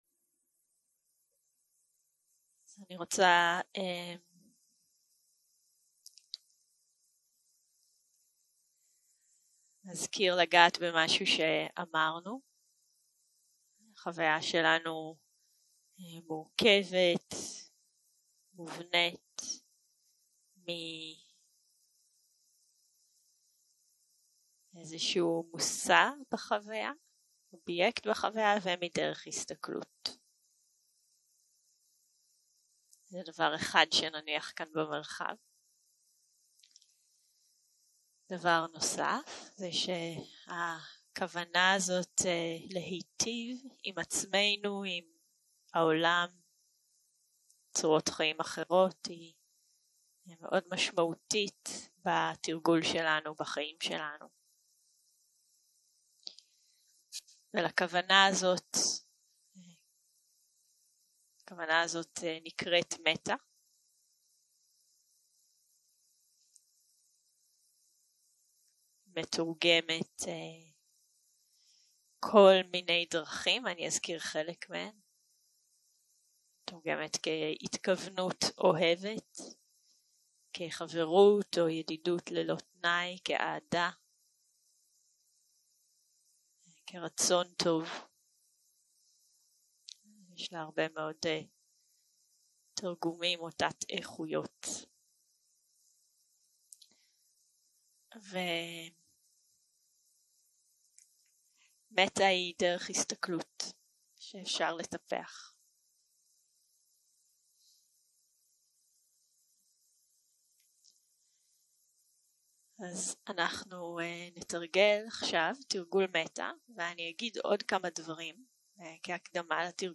יום 2 - הקלטה 3 - צהרים - מדיטציה מונחית - מטא לדמות הקלה
סוג ההקלטה: מדיטציה מונחית